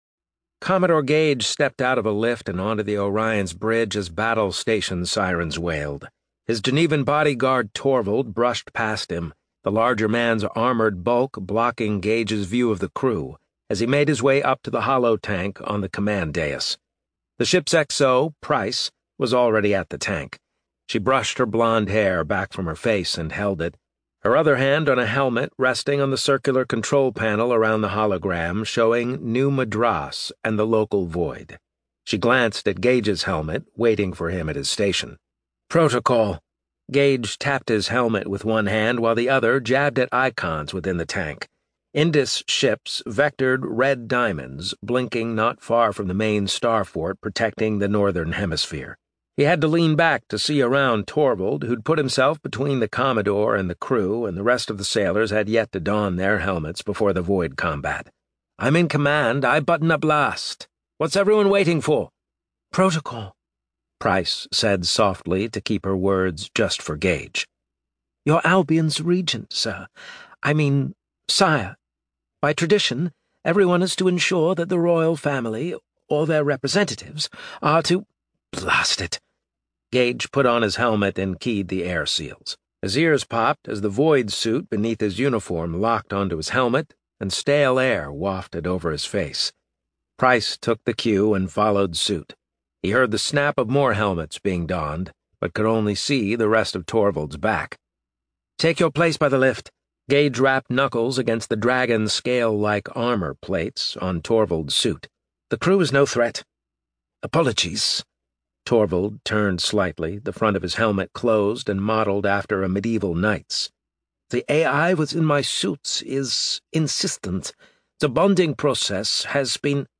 • Audiobook
A Macmillan Audio production from Tor Books